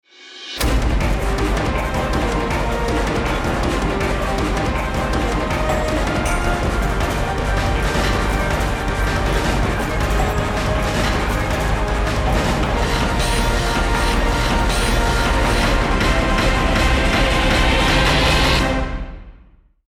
※一部の楽曲に収録の都合によりノイズが入る箇所があります。